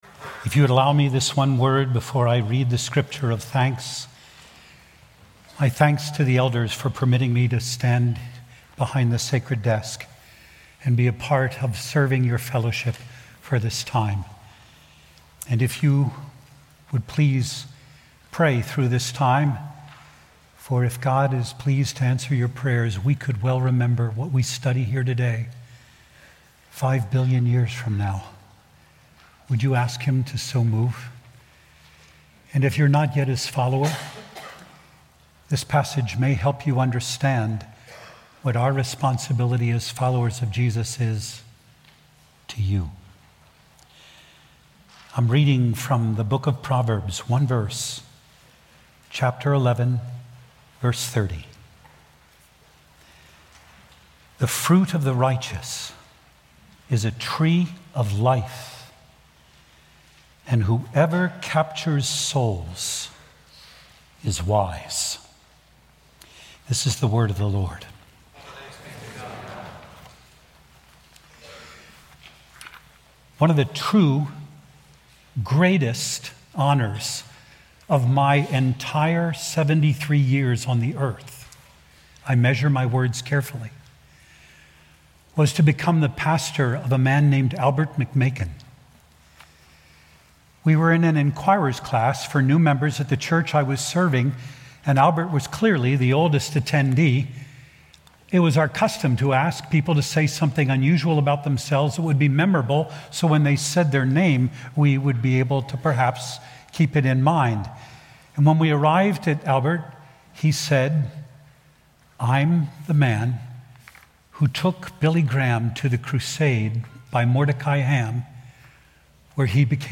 Proverbs 11:30 Sermon Points